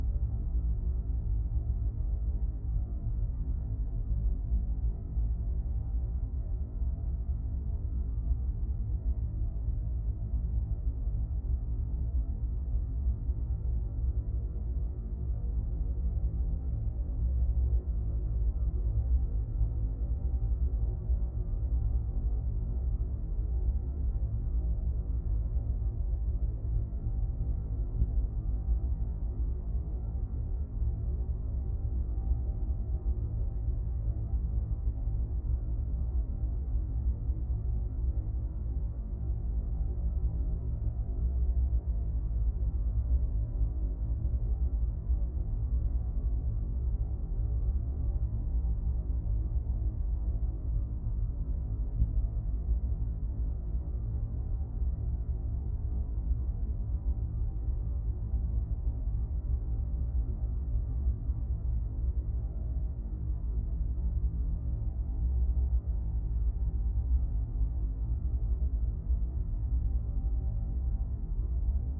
Low Rumble Loop 3.wav